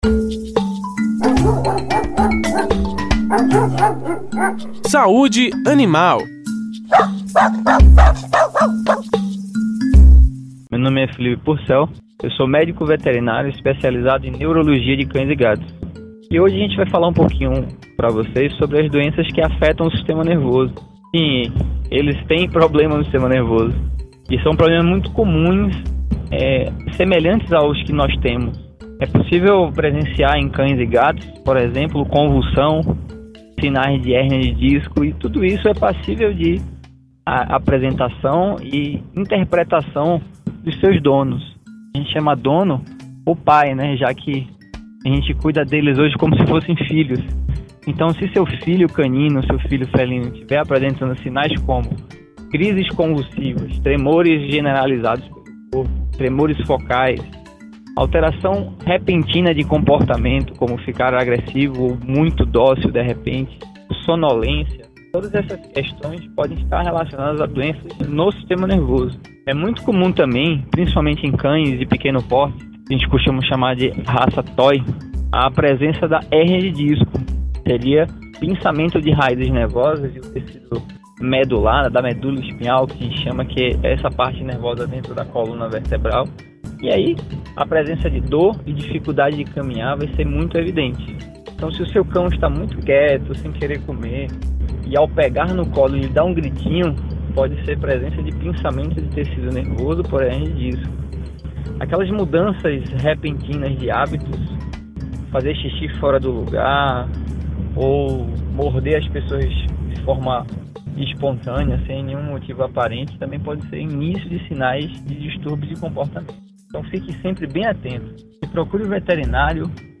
O tema foi abordado no quadro Saúde Animal, que vai ao ar toda quinta-feira, no Programa Saúde no ar, veiculado pelas Rádios Excelsior AM840 e Rádio Saúde no ar.